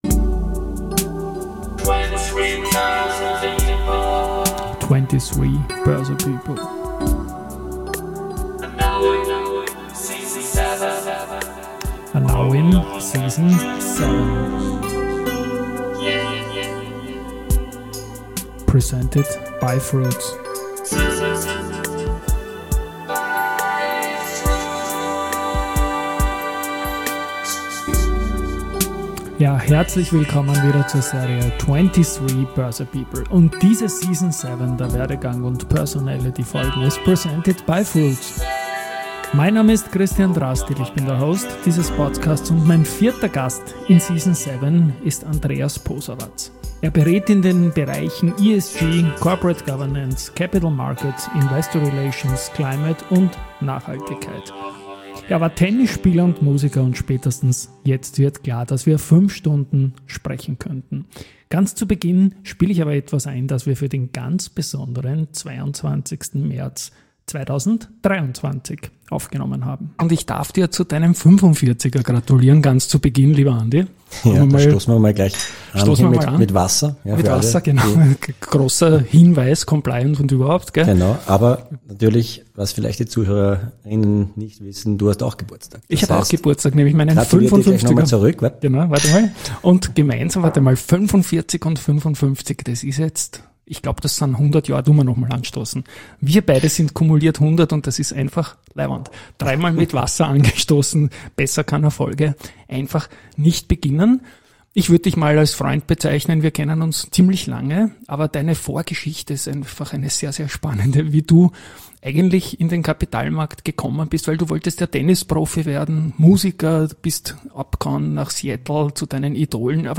Ganz zu Beginn spiele ich etwas ein, das wir für den 22. März aufgenommen haben. Wir reden über die Wunschberufe Tennisprofi (u.a. Battles vs. Lleyton Hewitt) oder Musiker (Grunge) und über Zufälle, die in den Kapitalmarkt führten.
Es handelt sich dabei um typische Personality- und Werdegang-Gespräche.